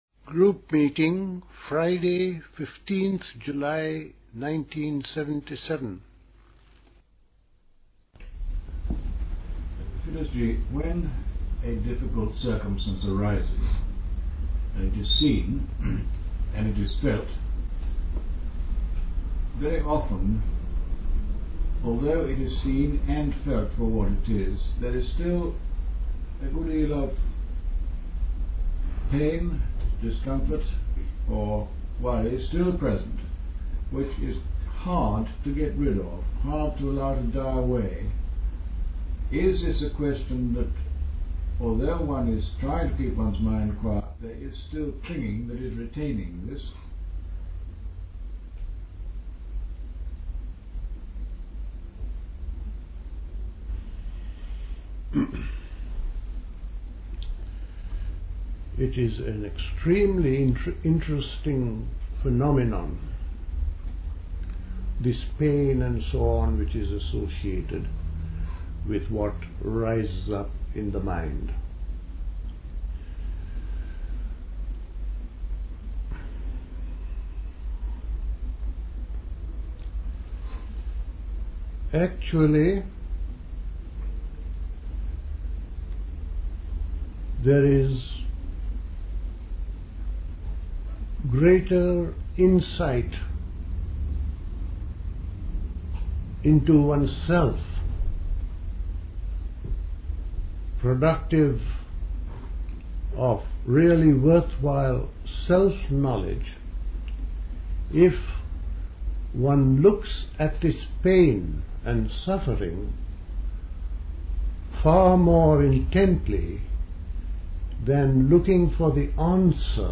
Questions and answers concerning pain and suffering and their origin. Suffering is due to grasping.